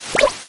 tnt_reload_03.ogg